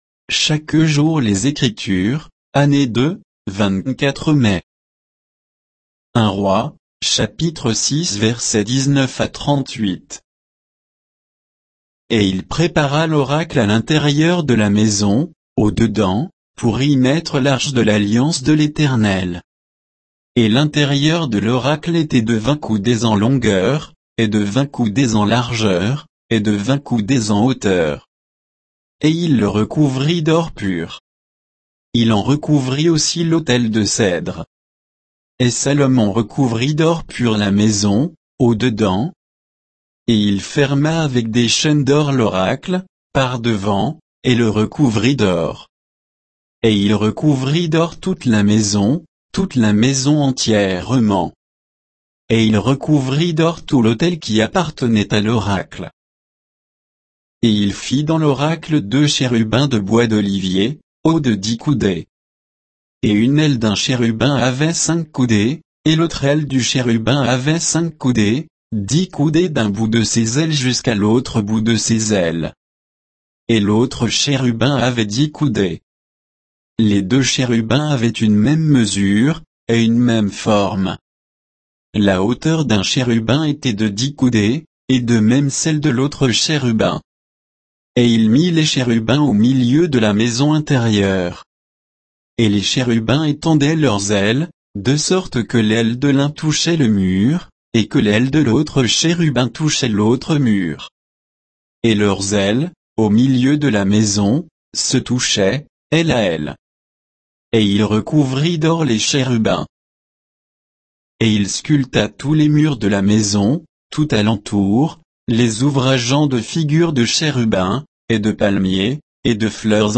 Méditation quoditienne de Chaque jour les Écritures sur 1 Rois 6, 19 à 38